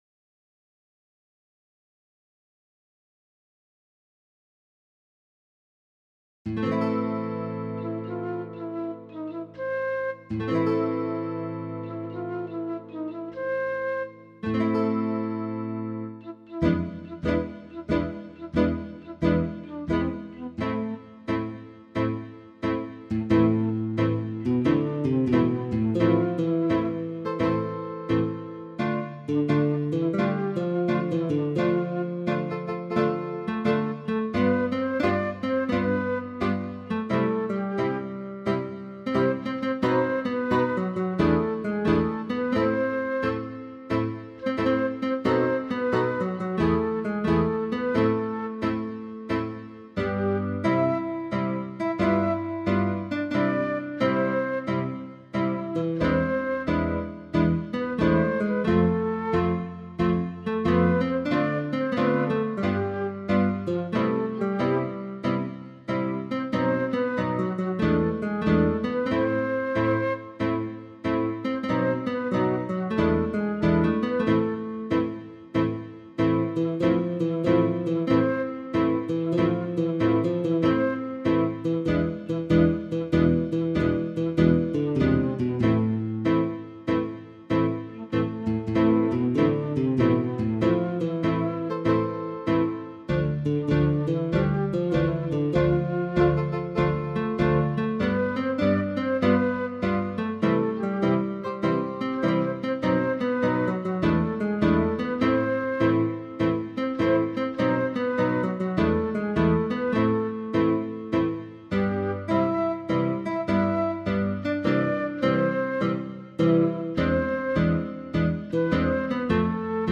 Мелодия